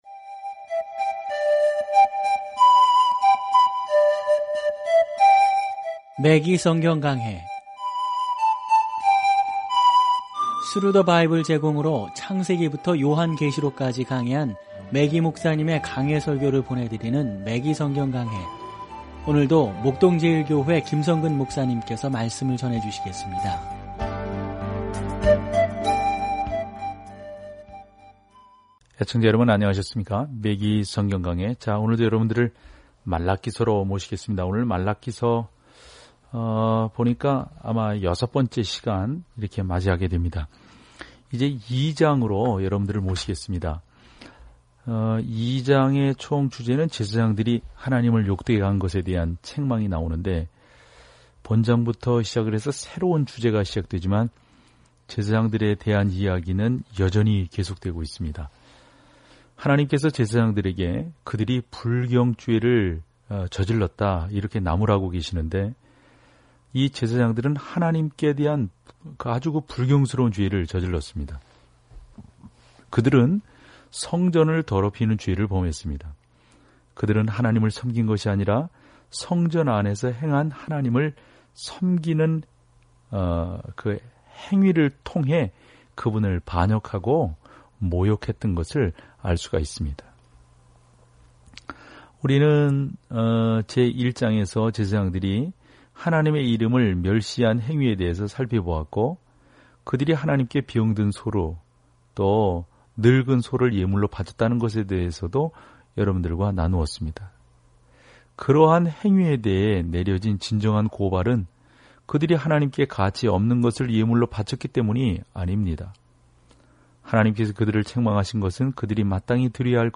오디오 공부를 듣고 하나님의 말씀에서 선택한 구절을 읽으면서 매일 말라기를 여행하세요.